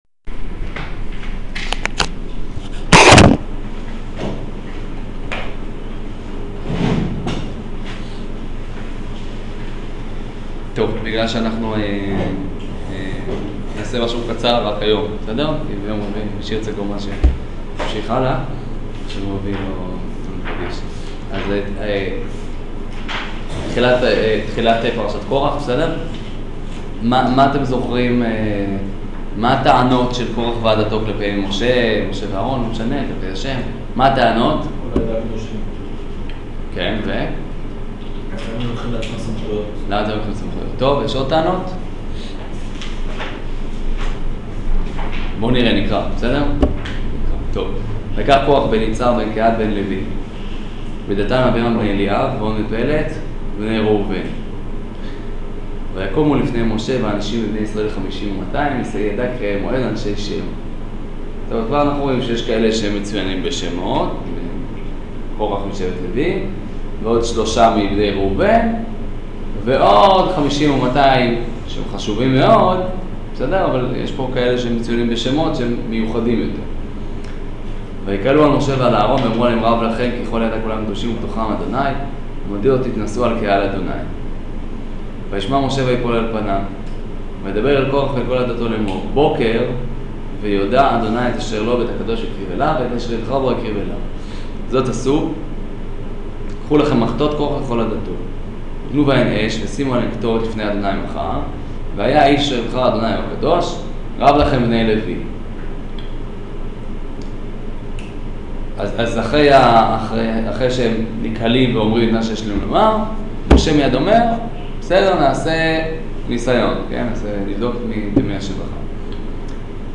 שיעור פרשת קורח